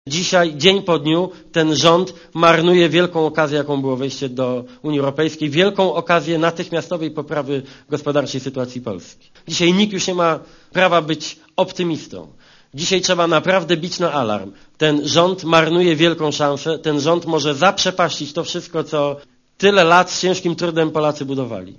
Dla Radia Zet mówi Donald Tusk (85 KB)